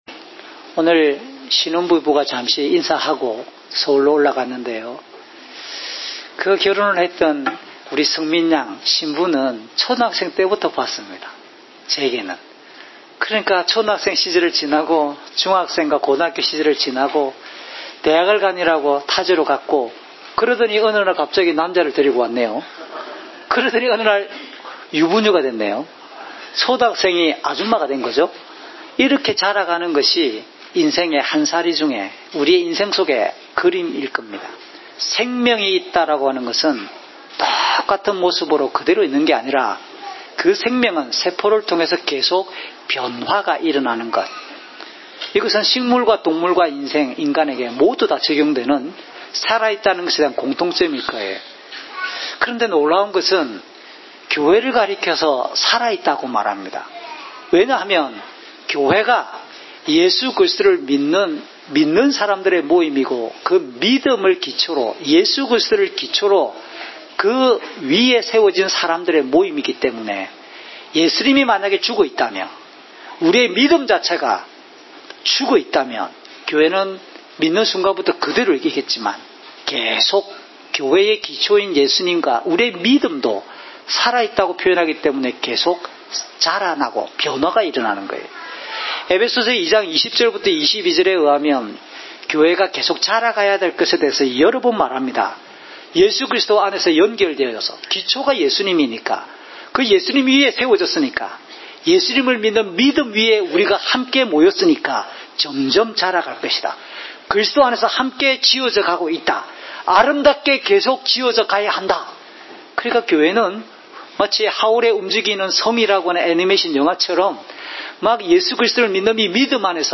주일설교 - 2019년 11월 10일 “교회는 진리와 사랑의 공동체입니다!"(요17:9~26)